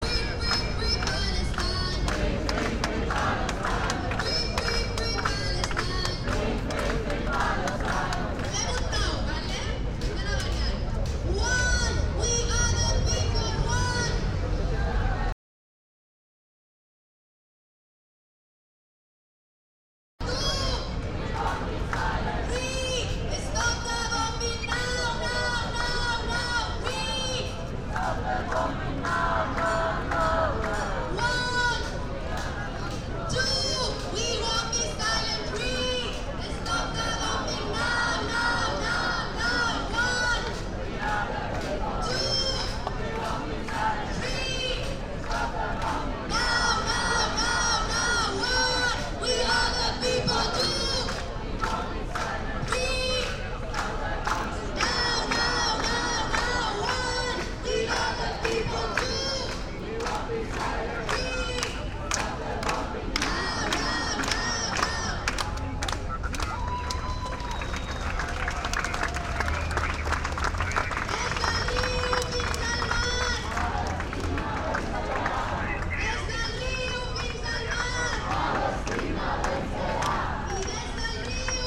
Live from Soundcamp: Radio With Palestine, Radio With Iran (Audio) Jun 25, 2025 shows Live from Soundcamp Live audio from demonstrations, actions and protests.